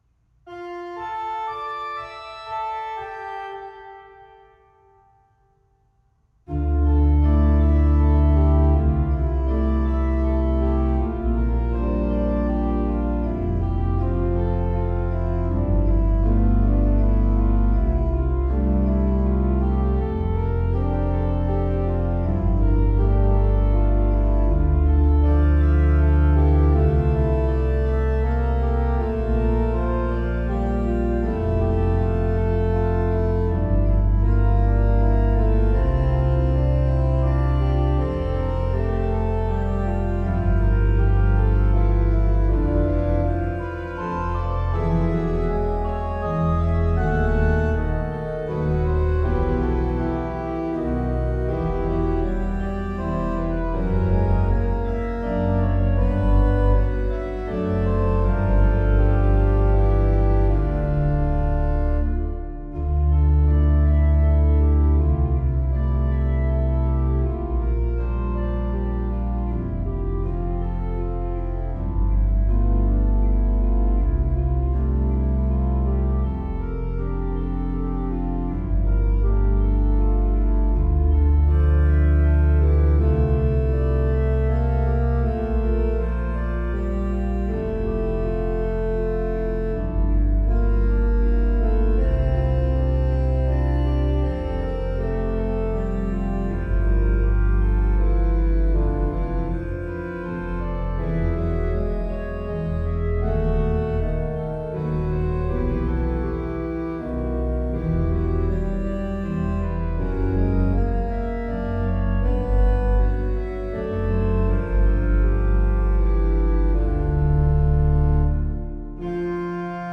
Orgel (2024)